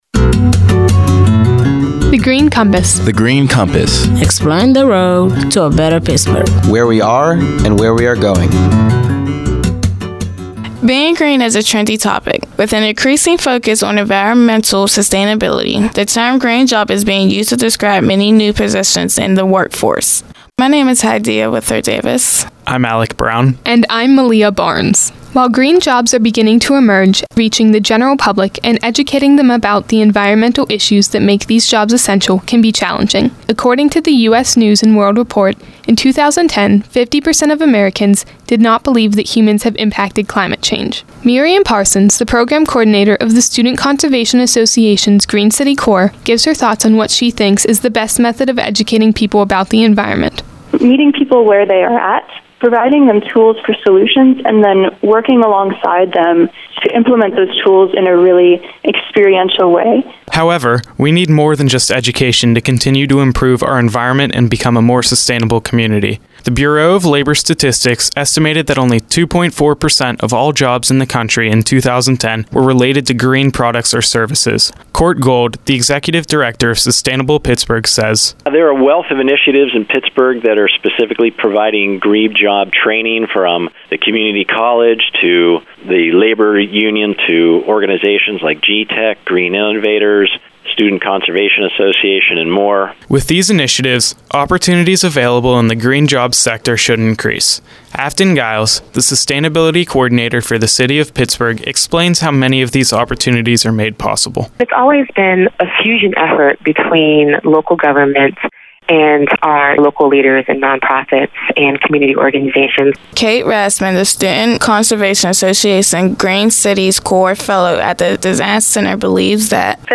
In July 2012, twenty-three students about to enter their first year of college created these nine radio features as Summer Youth Philanthropy Interns at The Heinz Endowments.
interview